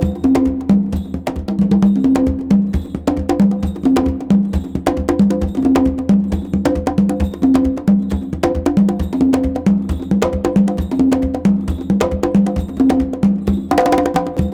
CONGABEAT5-R.wav